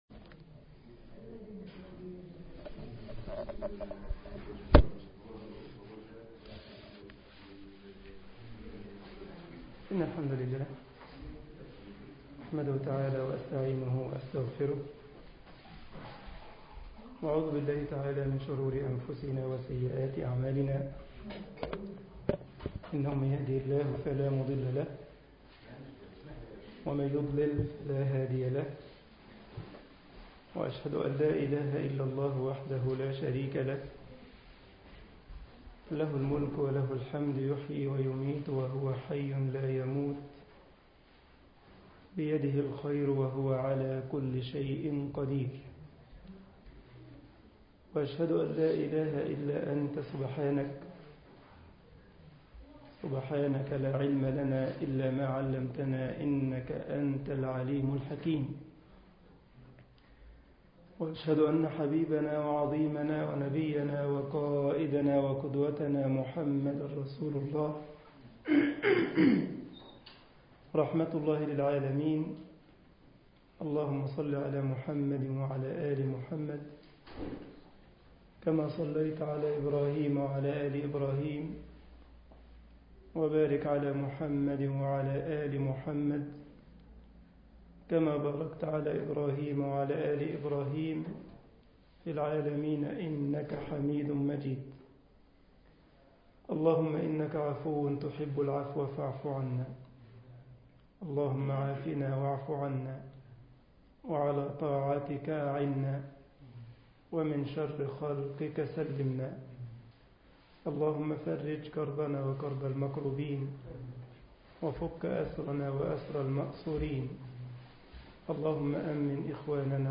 مسجد كايزرسلاوترن ـ ألمانيا محاضرة